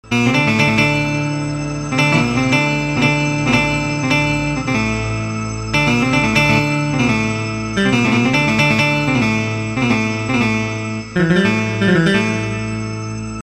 Asagida Dinlediginiz Sample Sesleri direk Orgla Calinip MP3 Olarak Kayit edilmistir
Elektro Baglama 2